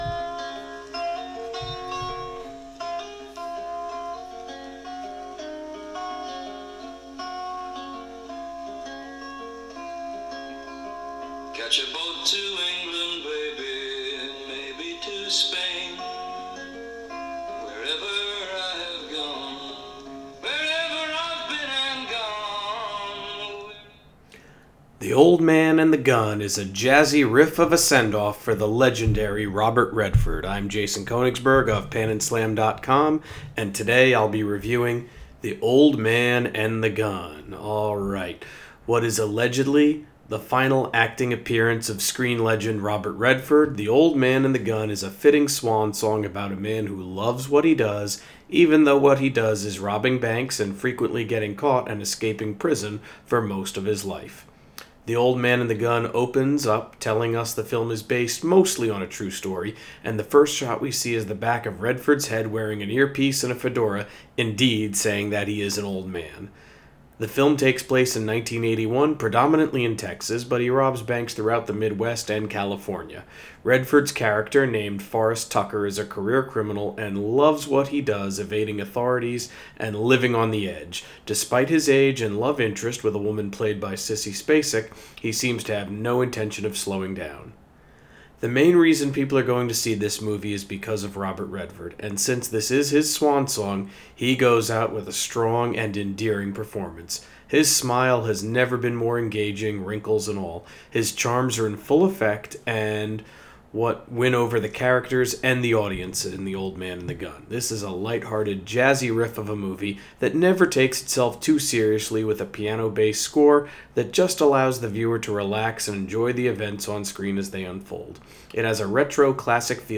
Movie Review: The Old Man and the Gun